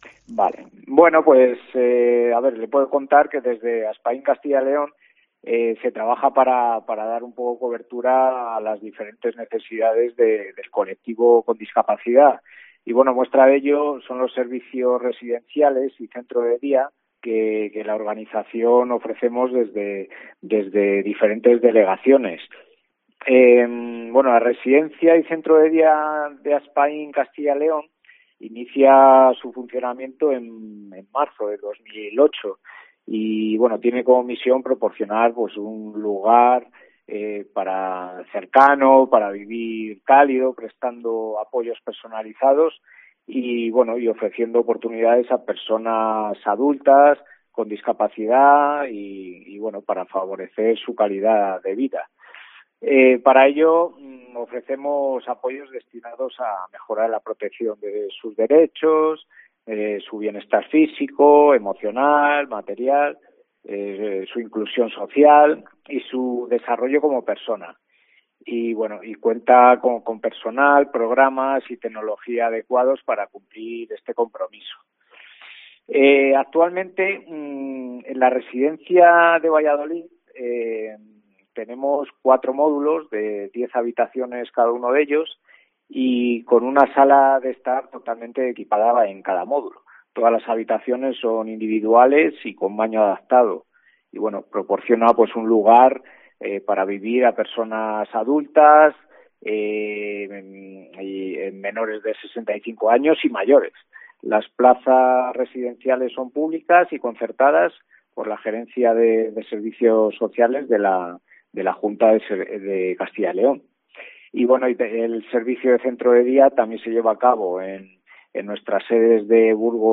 COPE entrevista